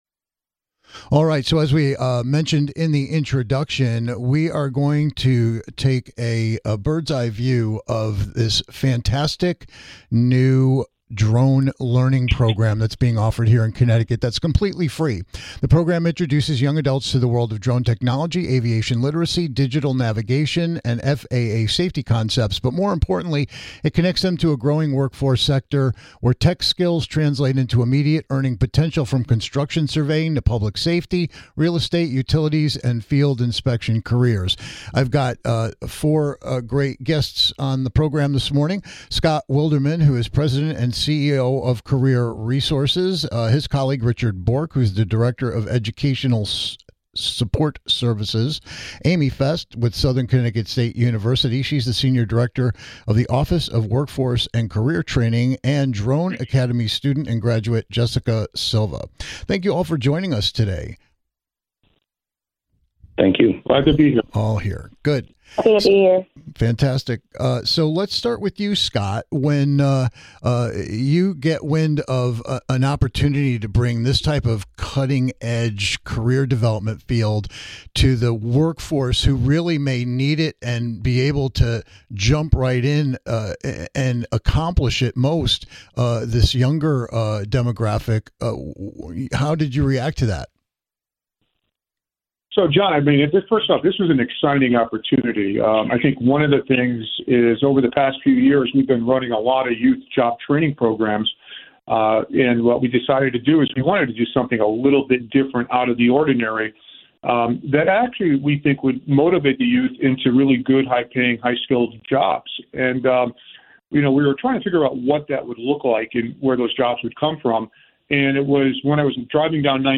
We're bringing you a bird's eye view of new career opportunities and training as we bring in a panel to talk about Connecticut's own Drone Academy - a pilot program developed in collaboration with The WorkPlace, Southern Connecticut State University (SCSU), and Career Resource...